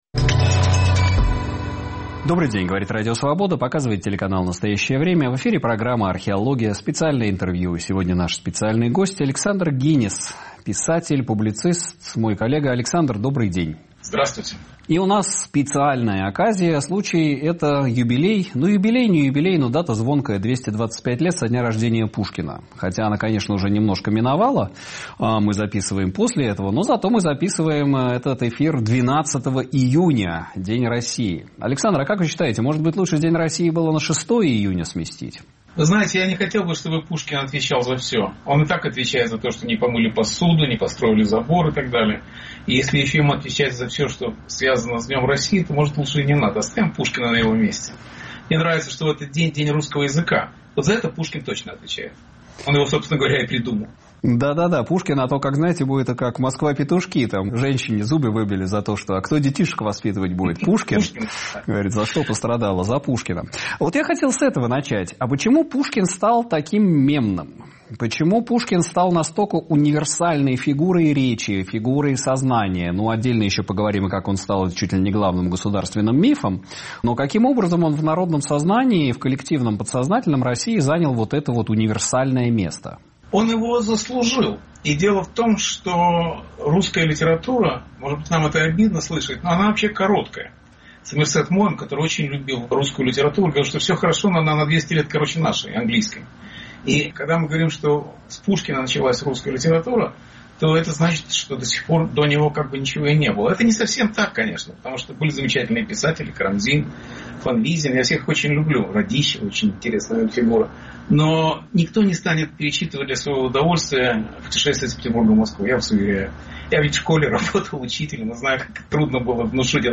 На вопросы Сергея Медведева отвечает писатель и публицист Александр Генис.